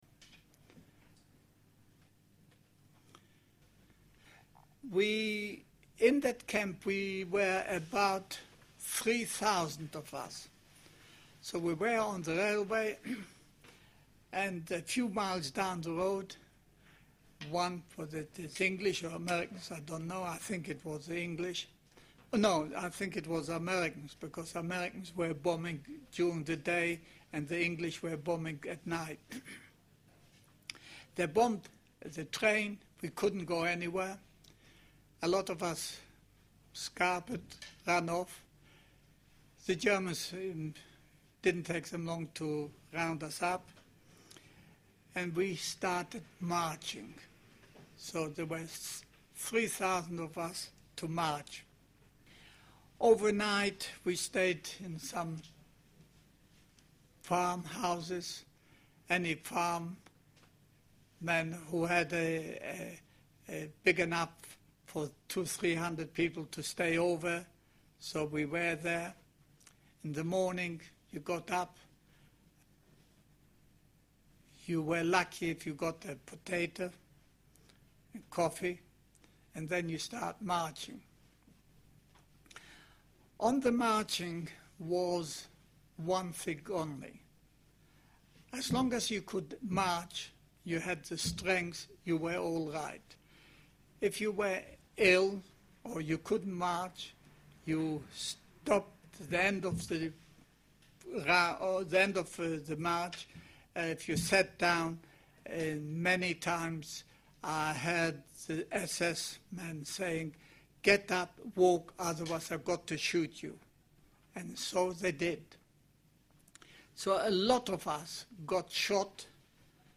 He described the death march at a Chabad meeting in Oxford in 2019